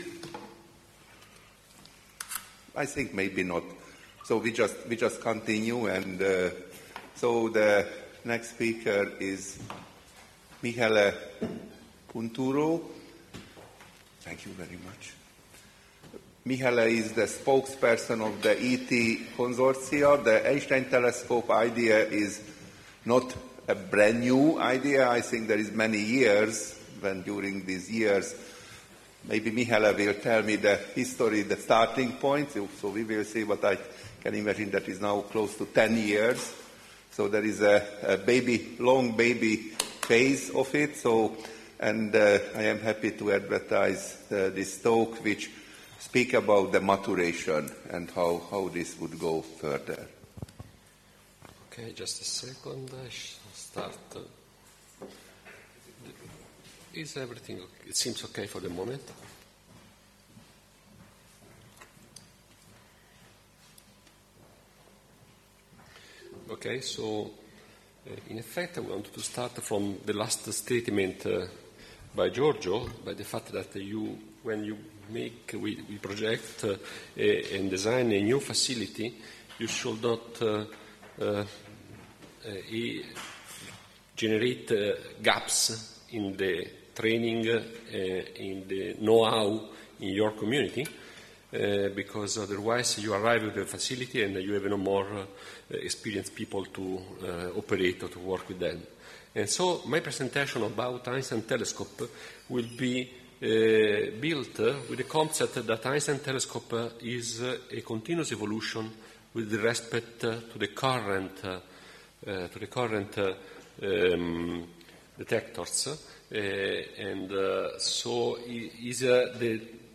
Az előadásról készült hanganyagott itt hallgathatod meg.